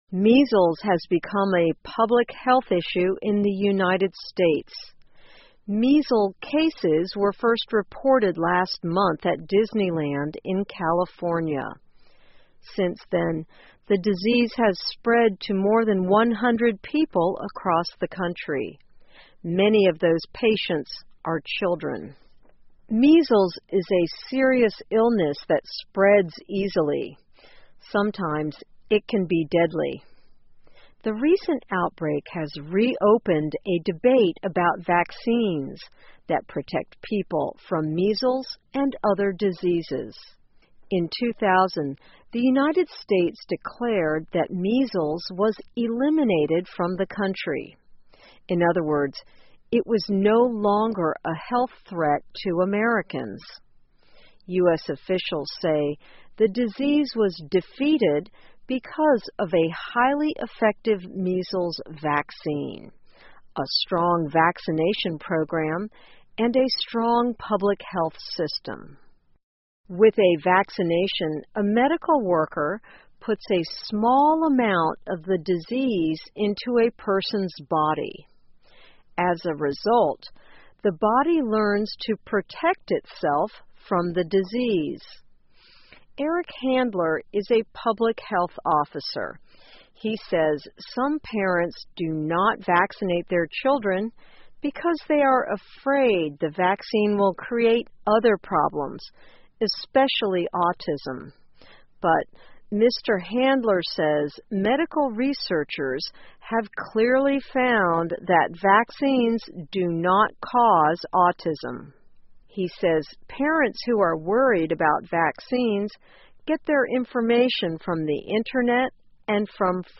VOA慢速英语2015 麻疹成为美国的医学、科学和政治问题 听力文件下载—在线英语听力室